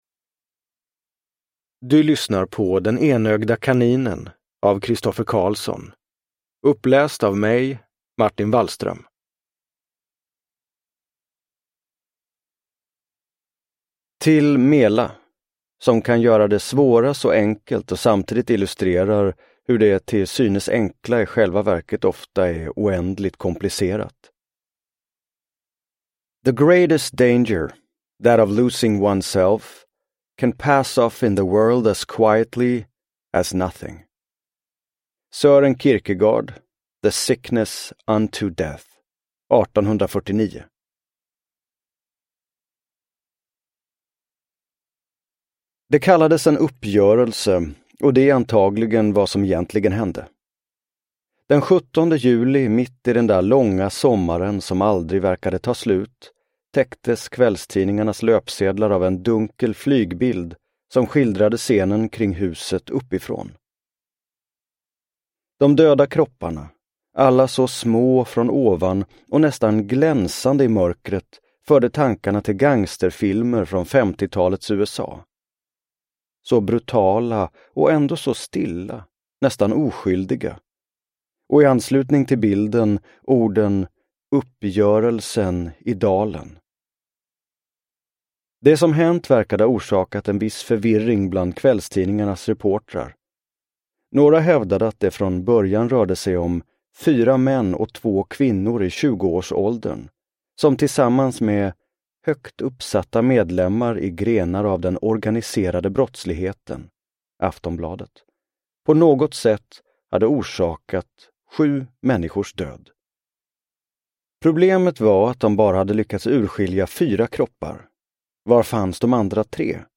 Den enögda kaninen / Ljudbok
Uppläsare: Martin Wallström